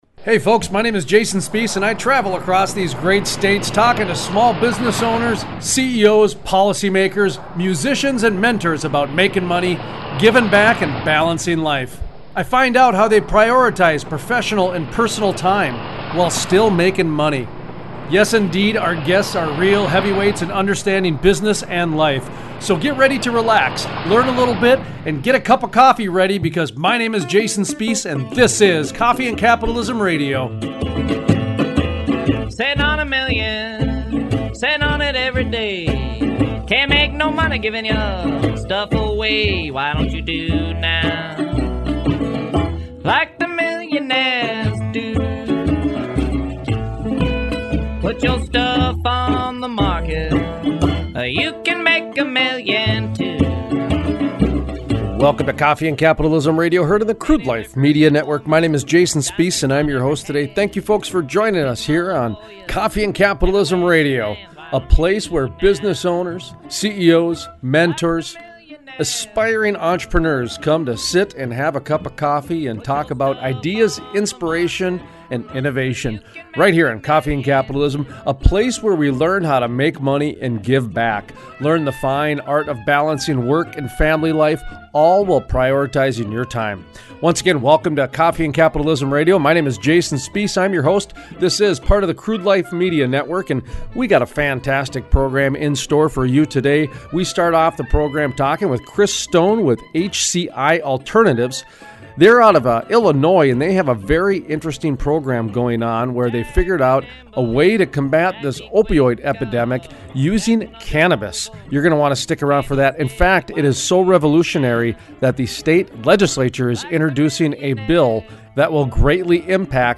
Small Business USA Interview: Austin Aries, professional wrestler, author, speaker Aries talks about how he left the WWE, a major corporation, to pursue his passions as an independent contractor.